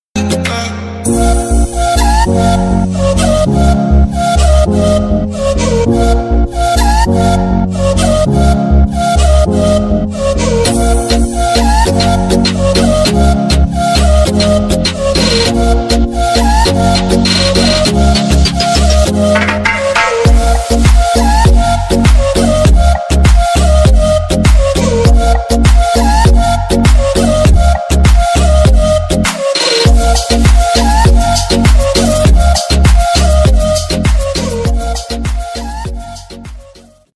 • Качество: 320, Stereo
deep house
Electronic
без слов
tropical house
Флейта